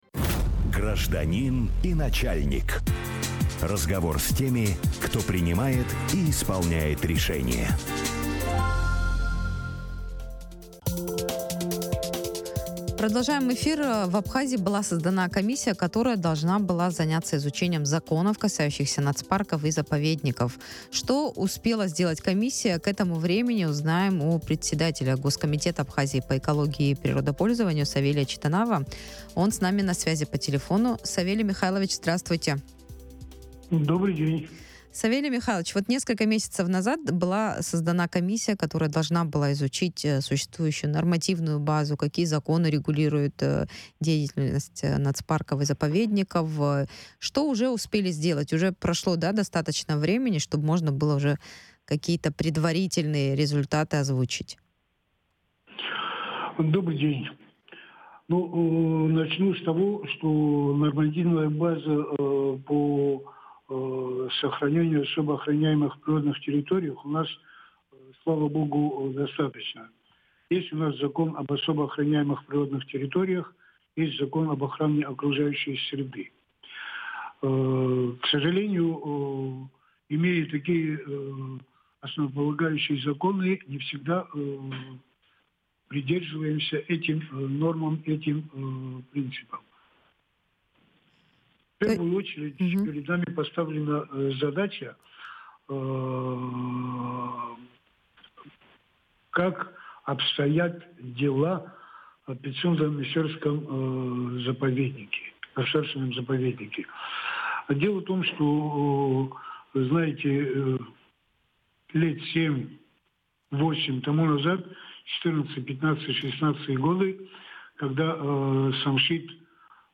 В Абхазии работает комиссия, в задачи которой входит определение четких границ нацпарков и заповедников. Что успела сделать комиссия к этому времени, рассказал председатель Госкомитета Абхазии по экологии и природопользованию Савелий...